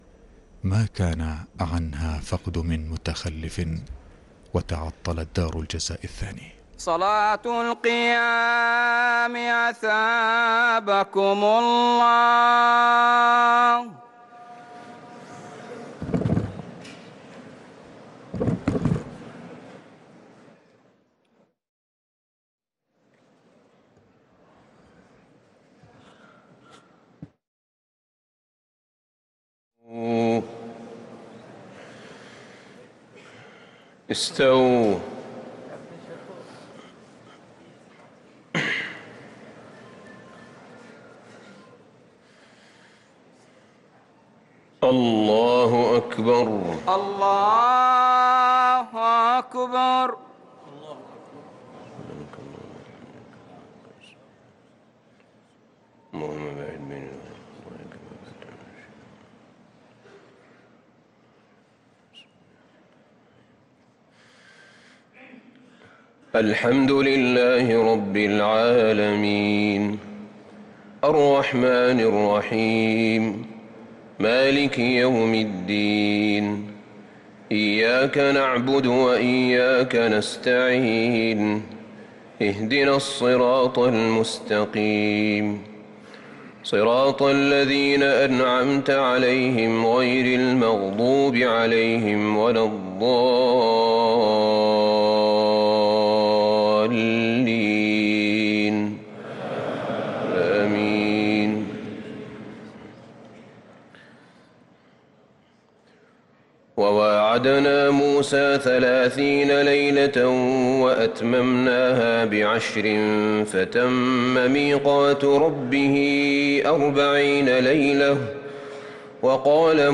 صلاة التراويح ليلة 12 رمضان 1444 للقارئ أحمد بن طالب حميد - الثلاث التسليمات الأولى صلاة التراويح